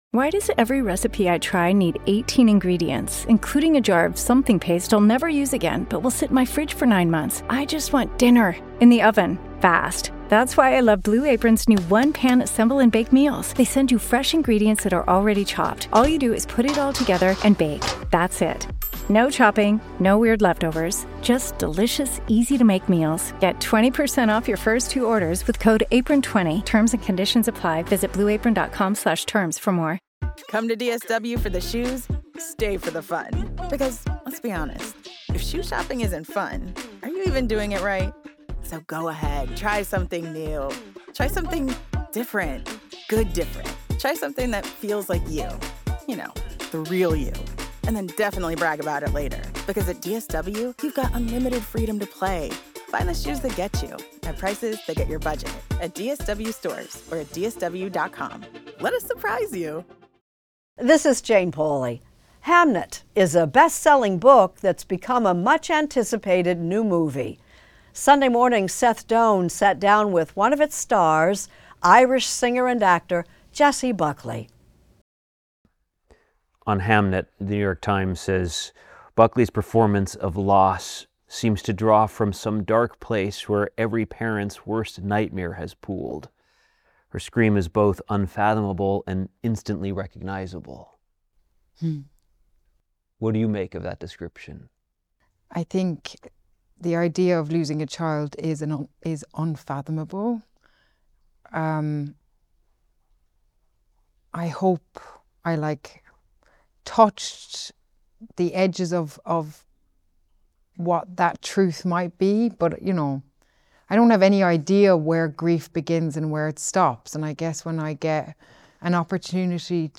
Extended Interview: Jessie Buckley